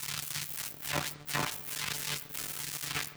SFX_Static_Electricity_Short_05.wav